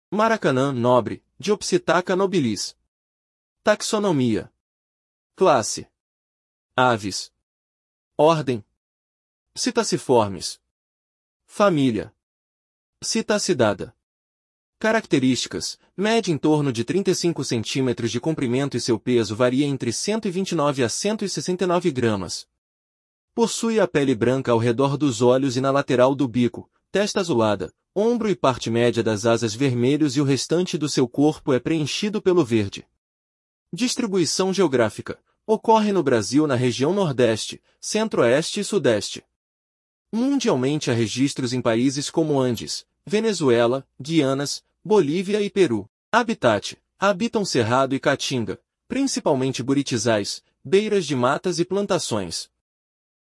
Maracanã -nobre (Diopsittaca nobilis)
Classe Aves